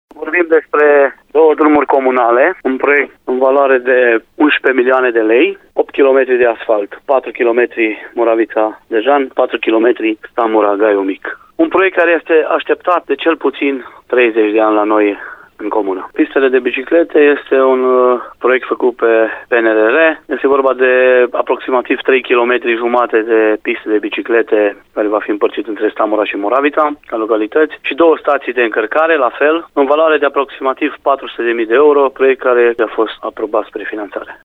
Vor fi asfaltate drumurile comunale Moravița – Dejan și Stamora Germană – Gaiu Mic, investiție așteptată de 30 de ani, spune primarul comunei, Remus Brazdău.